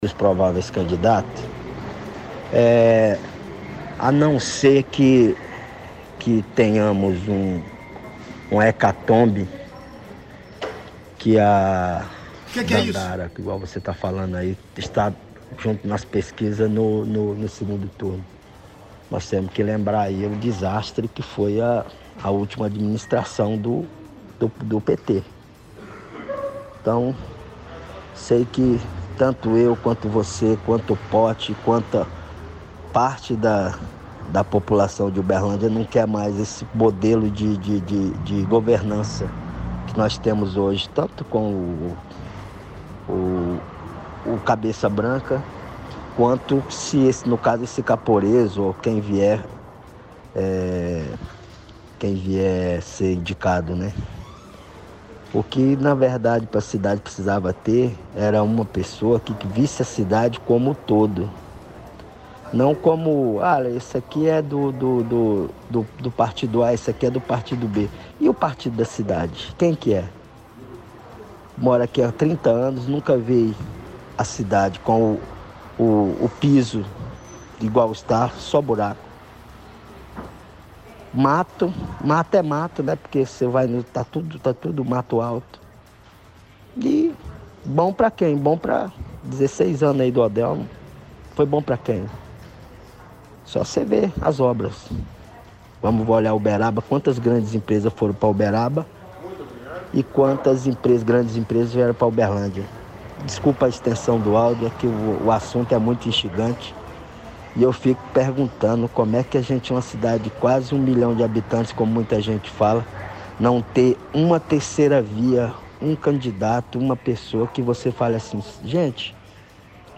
– Ouvinte fala sobre conjecturas dos prováveis candidatos à prefeitura, diz que foi um desastre a administração do PT e população de Uberlândia não quer passar por isso de novo. Comenta que cidade precisa de governante que veja a cidade como um todo, não fique preso às politicagens, compara quantas empresas foram para Uberaba e quantas vieram para Uberlândia.